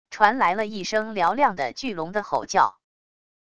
传来了一声嘹亮的巨龙的吼叫wav音频